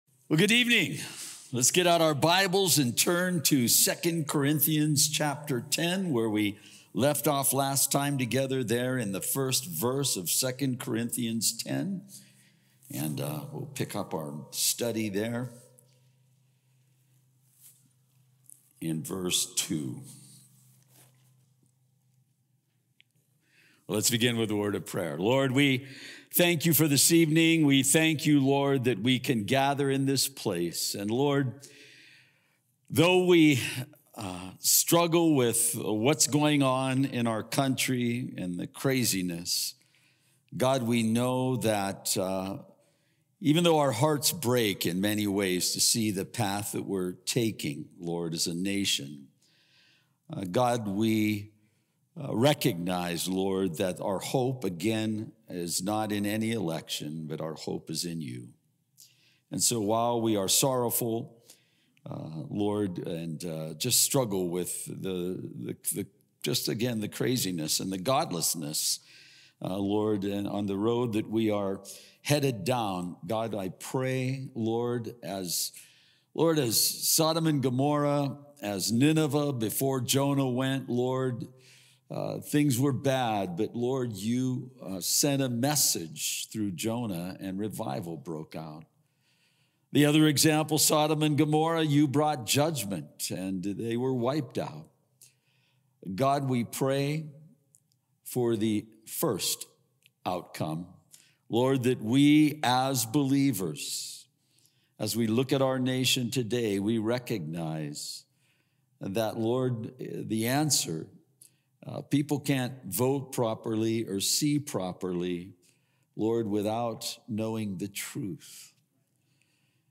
Sermon Details Calvary Chapel High Desert